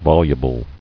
[vol·u·ble]